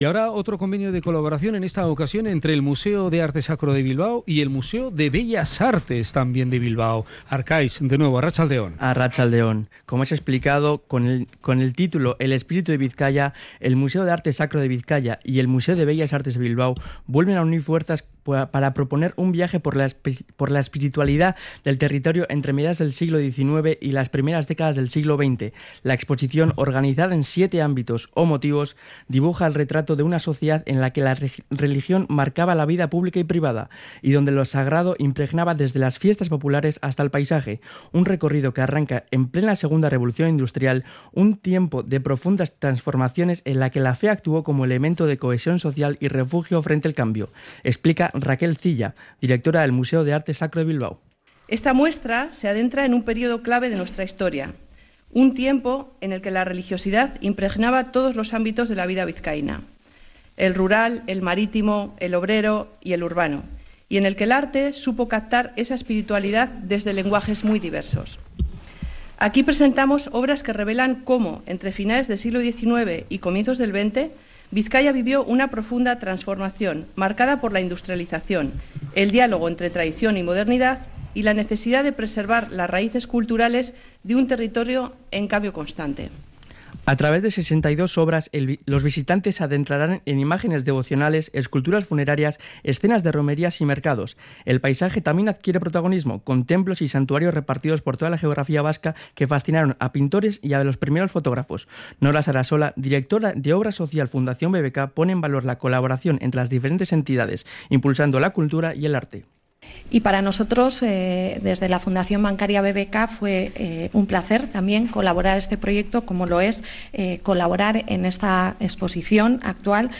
CRONICA-MUSEO-SACRO.mp3